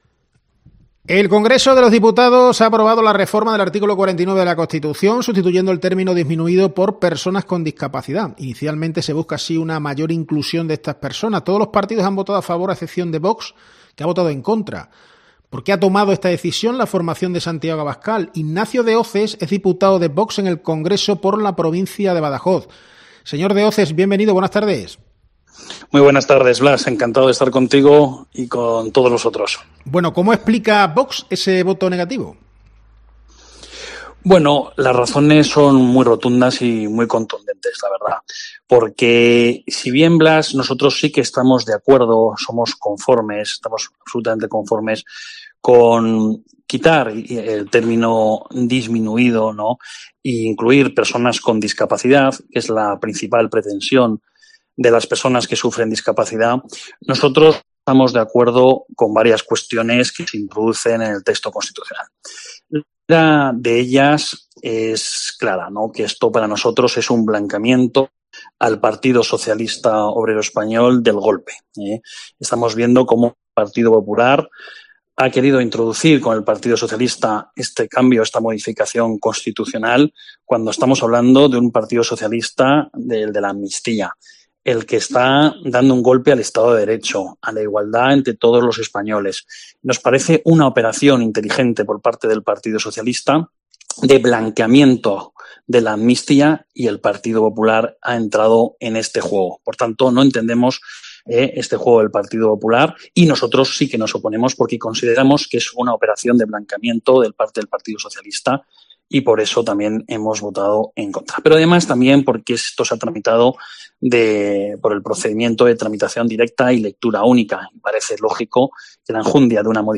En COPE hemos hablado con Ignacio de Hoces, diputado de Vox en el Congreso por la provincia de Badajoz.